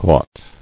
[ghat]